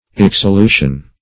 Search Result for " exsolution" : The Collaborative International Dictionary of English v.0.48: Exsolution \Ex`so*lu"tion\, n. [L. exsolutio a release.]